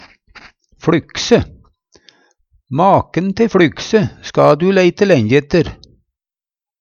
fLykse - Numedalsmål (en-US)